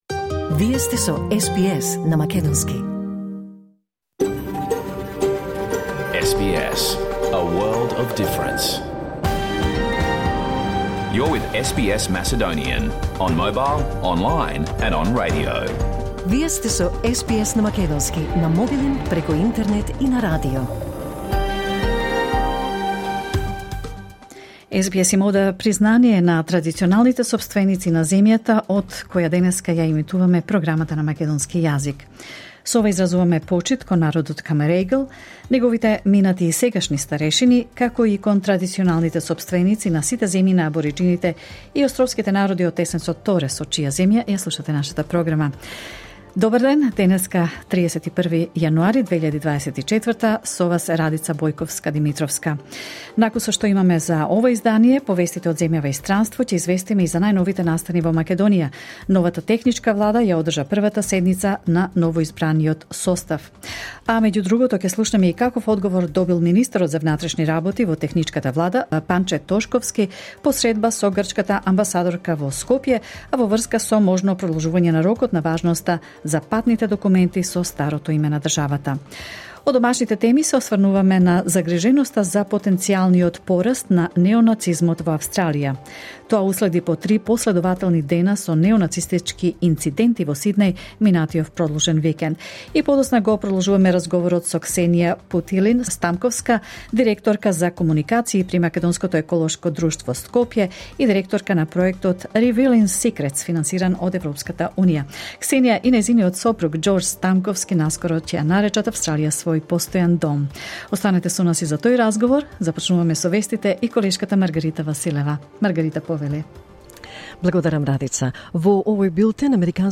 SBS Macedonian Program Live on Air 31 January 2024